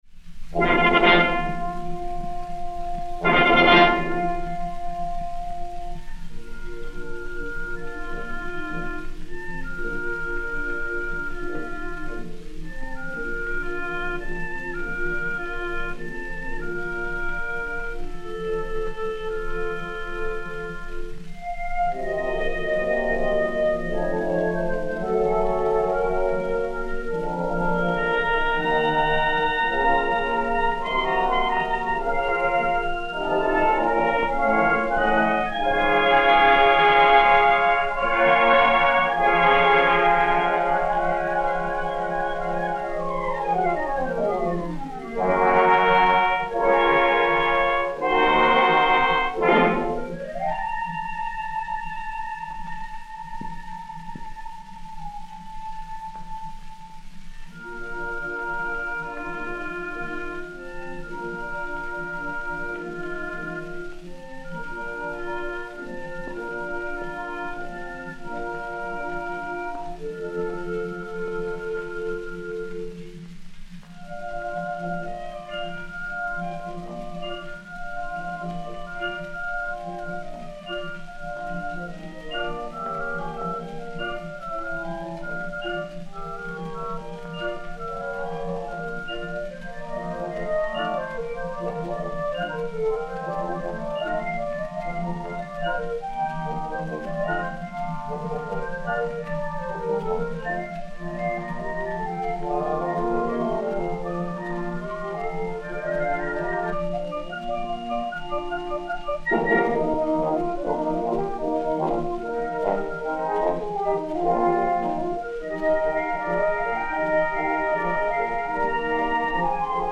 1. Andantino Allegretto - 2. Presto
Musique de la Garde Républicaine dir César Bourgeois